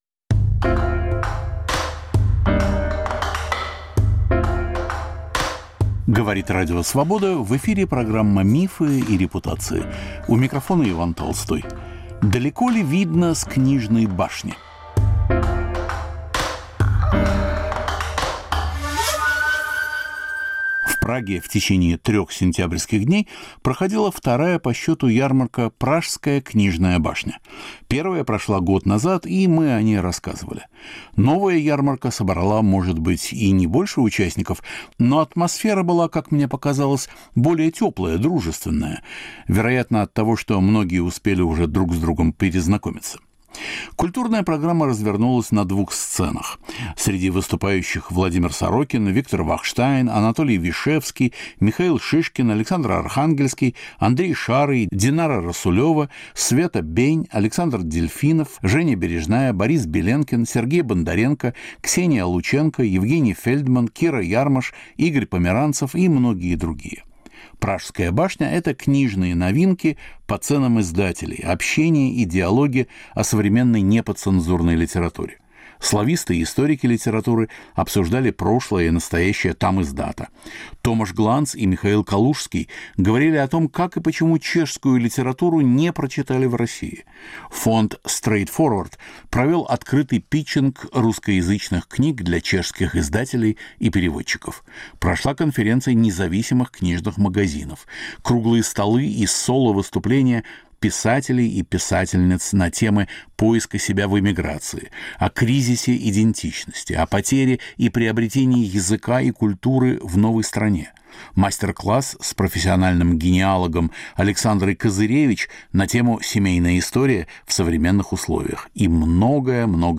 Разговор о книгах, эмиграции, настоящем и будущем.
В Праге во второй раз проходила ярмарка "Пражская книжная башня" (12-14 сентября). Что представляет собою новый тамиздат? Предлагаем разговоры с участниками события.